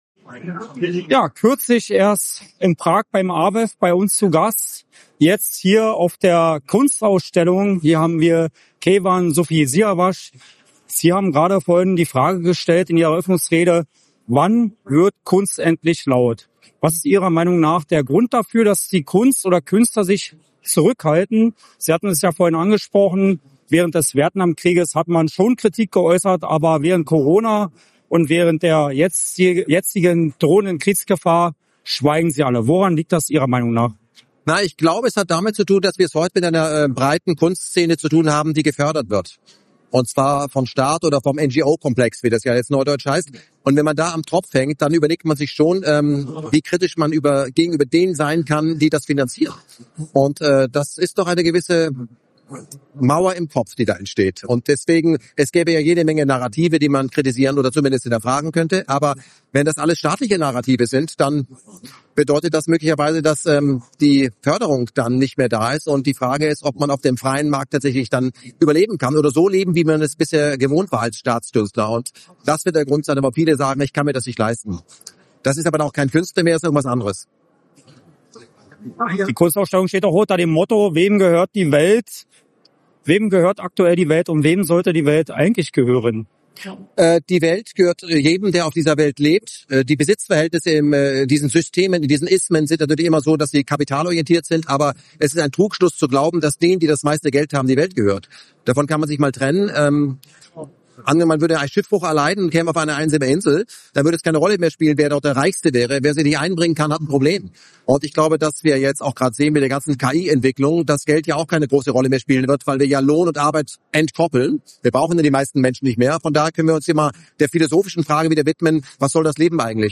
am Rande der alternativen Kunstausstellung „Wem gehört die Welt?“
in Berlin.